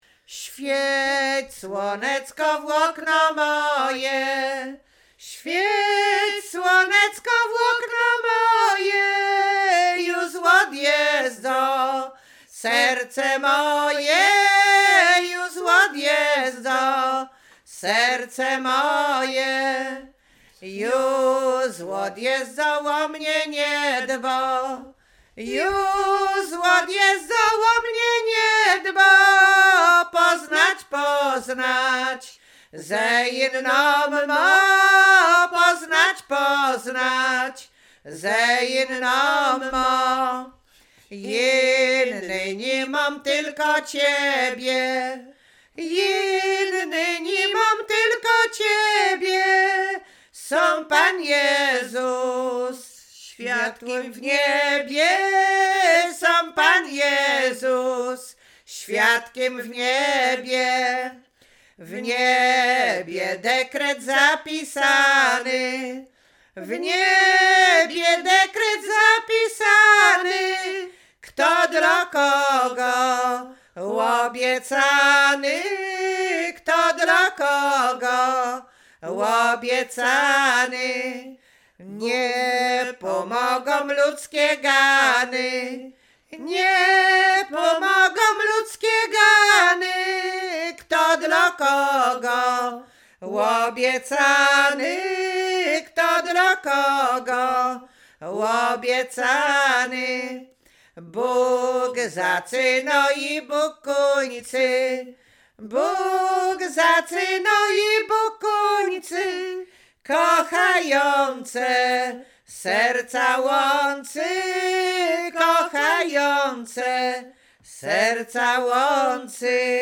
Łowickie
liryczne miłosne